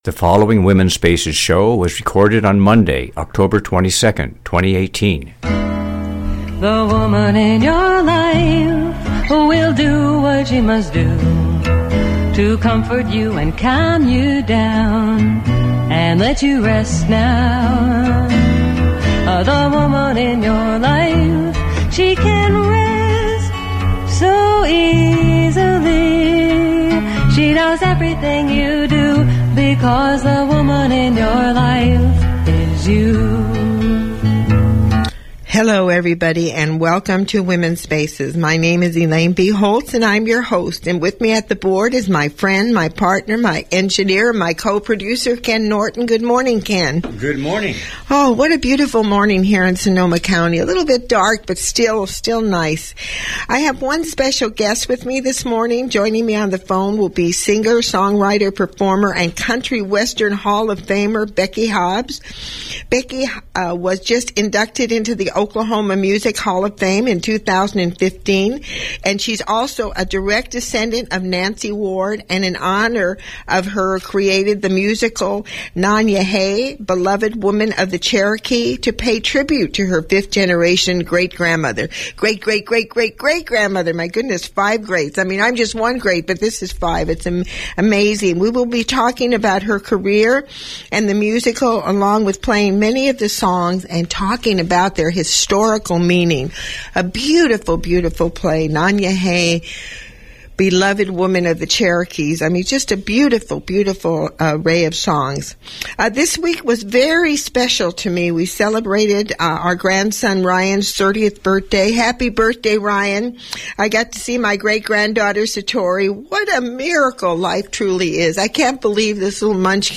Singer, Songwriter, Performer on Nanyehi